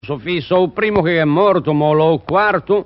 Si “ottiene” il dittongo “o-u” /'Ow/, che si trova riportato nella tabella dedicata ai “dittonghi discendenti” contenuta nell'articolo “I dittonghi e gli iati della lingua genovese” presente sul sito come “appendice” alle “Osservazioni sulla grafia di G. Casaccia”.